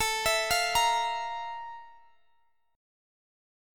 Listen to FM11/A strummed